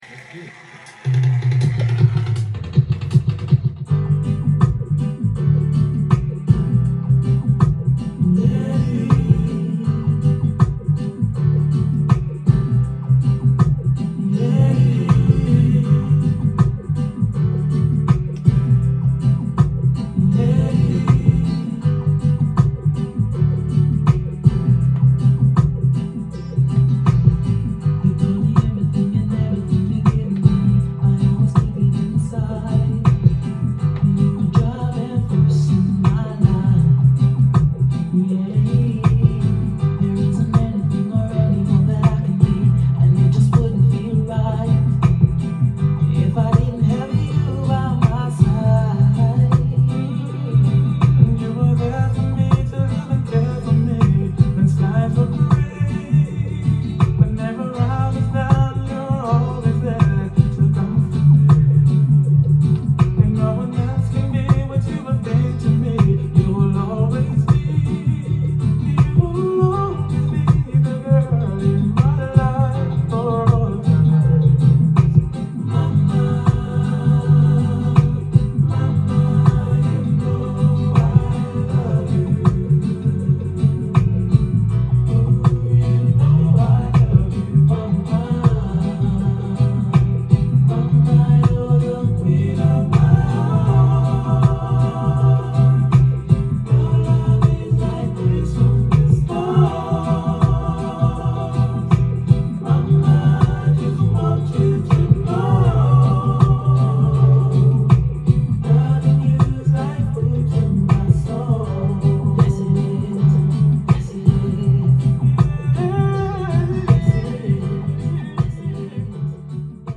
店頭で録音した音源の為、多少の外部音や音質の悪さはございますが、サンプルとしてご視聴ください。
音が稀にチリ・プツ出る程度